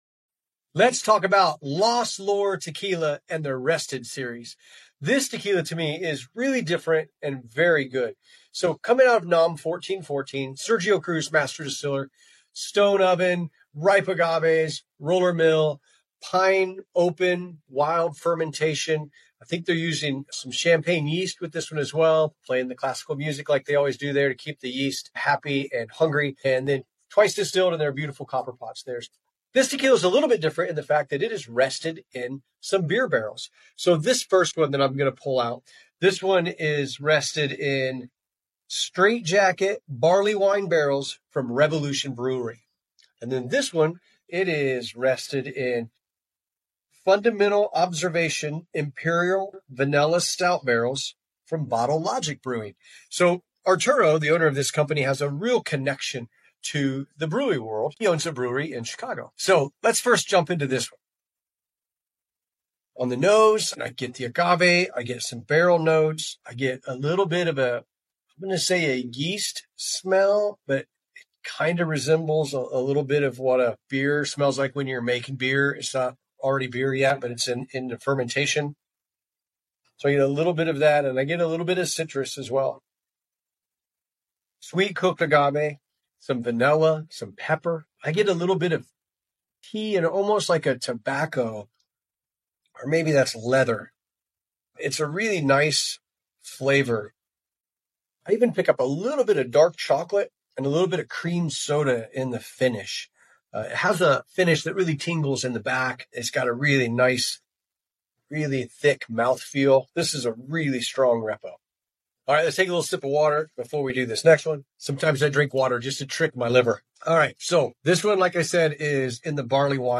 Each episode, we bring you candid conversations with master distillers, brand founders, and agave experts who share their stories, craft secrets, and passion for tequila. Whether you’re a seasoned aficionado or just beginning your tequila journey, join us as we explore the rich culture, traditions, and innovations shaping this iconic spirit.